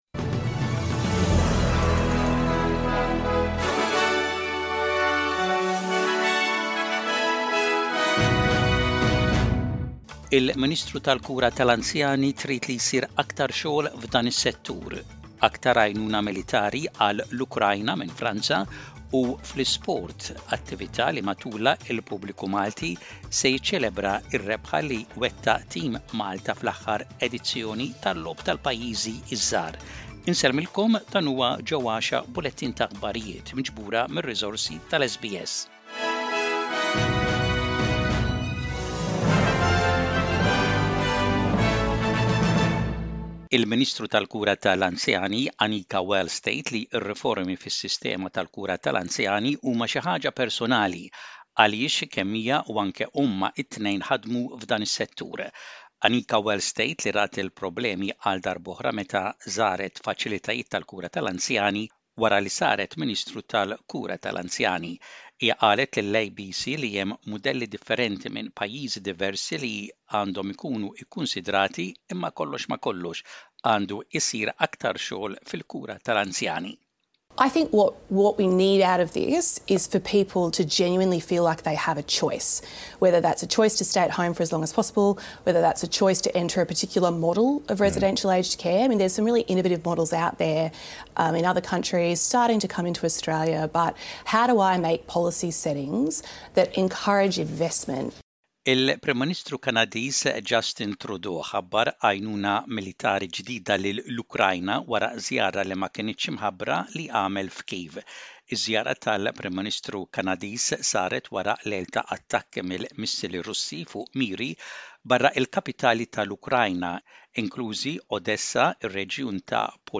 SBS Radio | Maltese News: 13/06/23